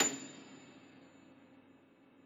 53g-pno29-A6.wav